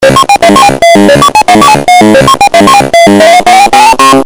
Pacman Estourado Efeito Sonoro: Soundboard Botão